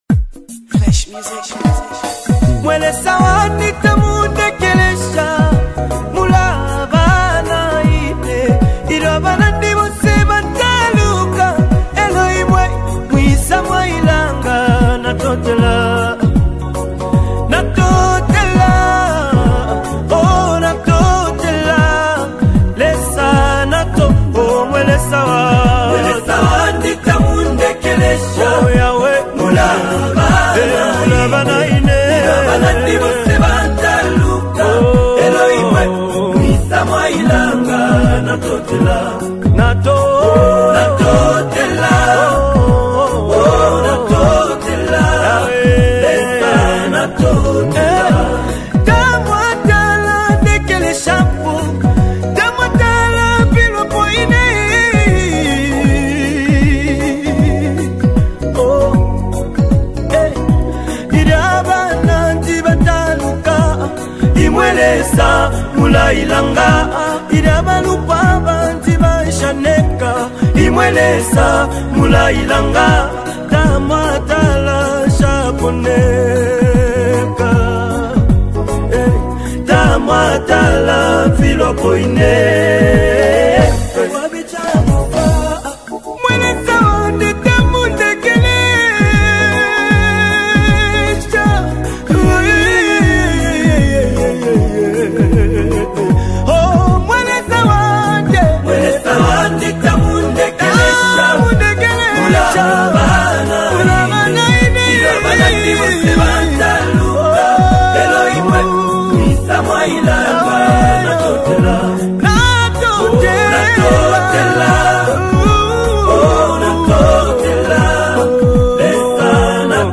LatestZambian Worship Songs
Through soul-stirring vocals and powerful lyrics
Don’t miss out on this powerful worship anthem!